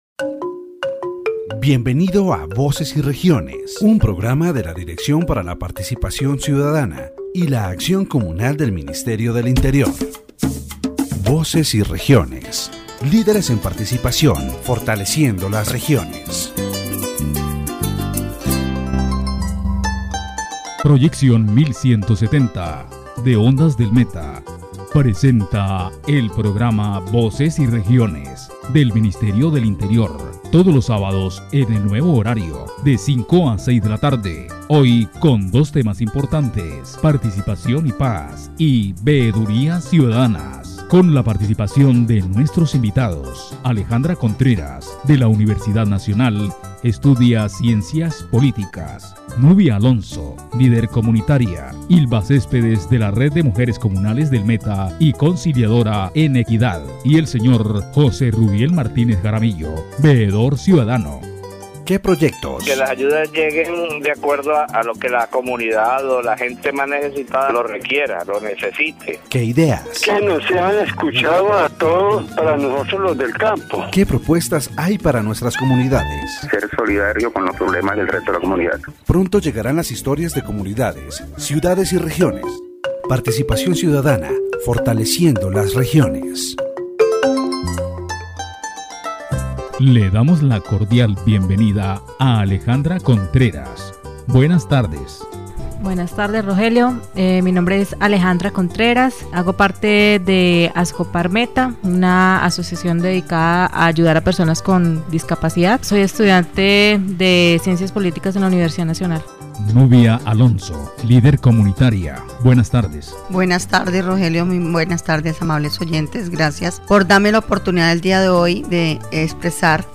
The radio program "Voces y Regiones" addresses various critical issues facing Colombian society, including citizen participation, peacebuilding, and the rights of marginalized groups. The debate highlights the importance of community participation in public policy formulation, the challenges faced by people with disabilities, and the complex nature of the peace process. The key topics discussed in this program are: citizen participation, peacebuilding, rights of marginalized groups, corruption and government accountability.